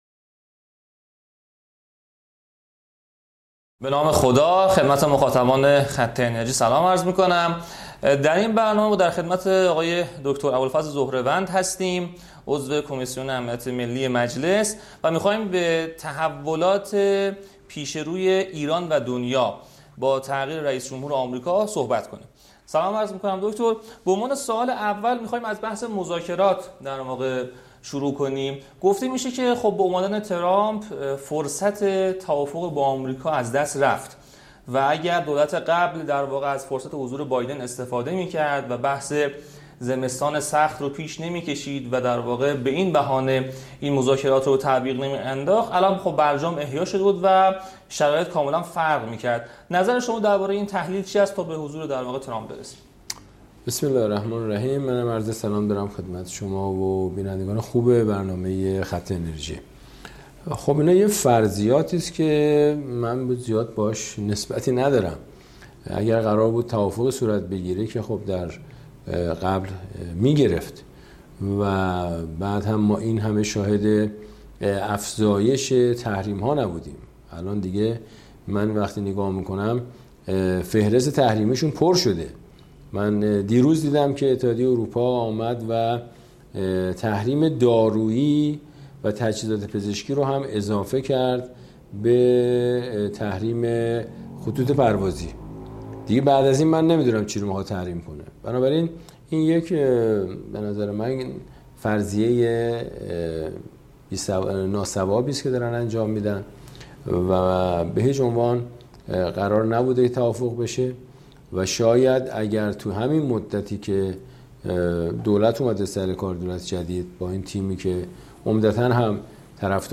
حال در این شرایط، دولت ایران باید چه سیاستی به خرج دهد؟ ابوالفضل ظهره‌وند عضو کمیسیون امنیت ملی مجلس توضیح می‌دهد.